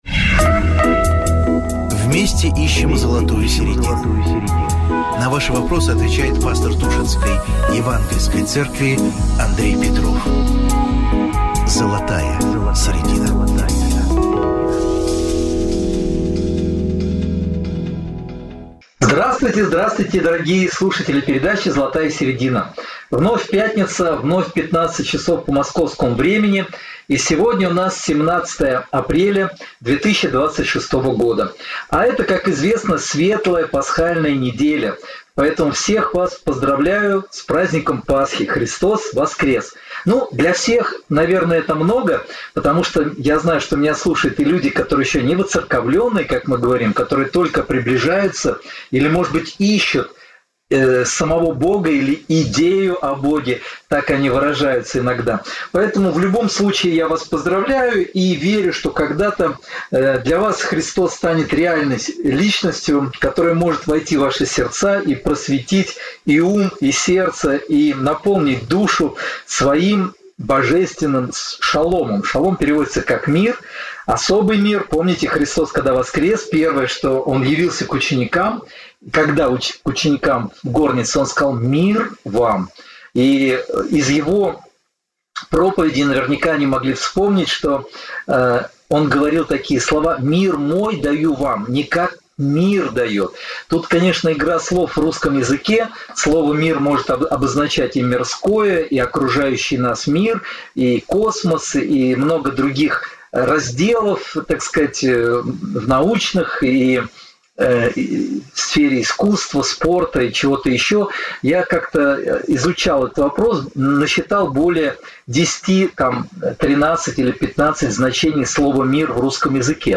Размышления на актуальные темы и ответы на вопросы слушателей. Ведущий: пастор